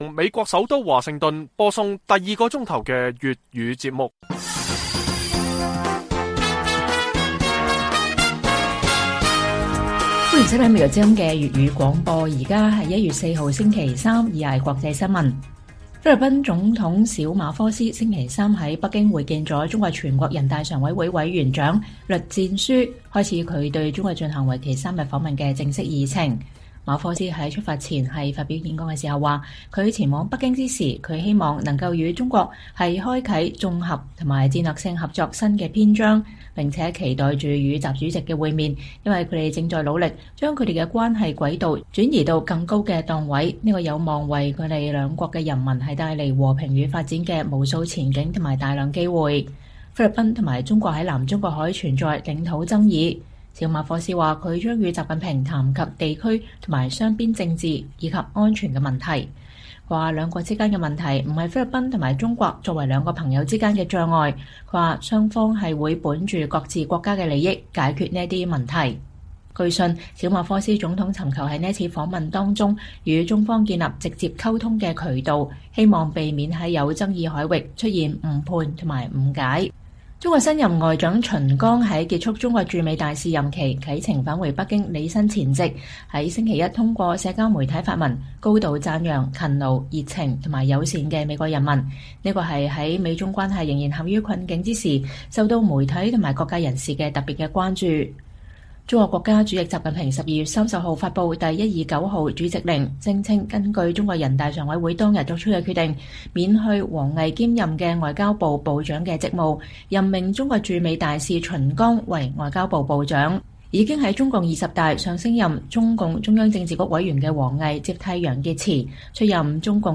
粵語新聞 晚上10-11點: 著名香港作曲家顧嘉煇逝世